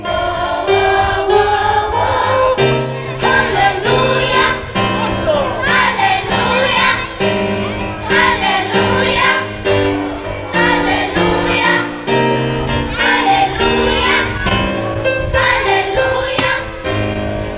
W tej chwili trwają próby i ostatnie przygotowania.